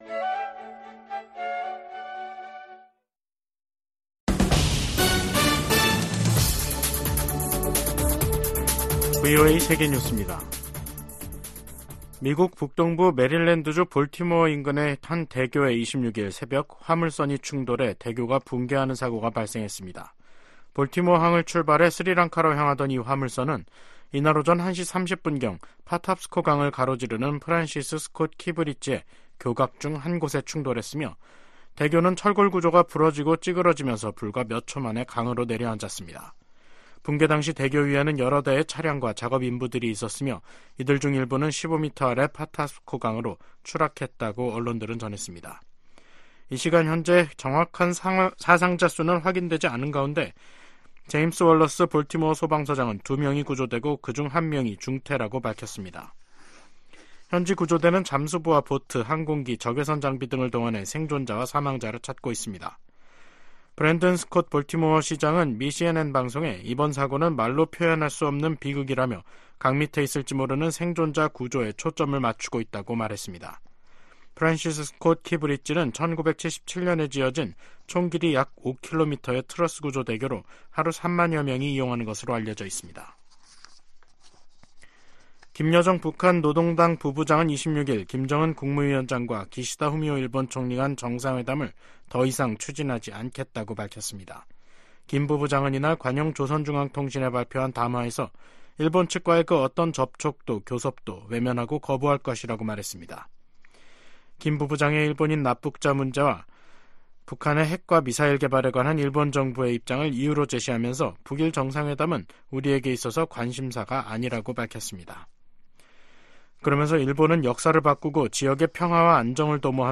세계 뉴스와 함께 미국의 모든 것을 소개하는 '생방송 여기는 워싱턴입니다', 2024년 3월 26일 저녁 방송입니다. '지구촌 오늘'에서는 미국이 가자지구 휴전을 촉구하는 유엔 안전보장이사회 결의안에 기권한 것에 반발해 이스라엘이 미국과의 고위급 회담을 취소한 소식 전해드리고, '아메리카 나우'에서는 도널드 트럼프 전 대통령의 '성추문 입막음' 관련 혐의 형사 재판이 다음 달 15일 시작되는 이야기 살펴보겠습니다.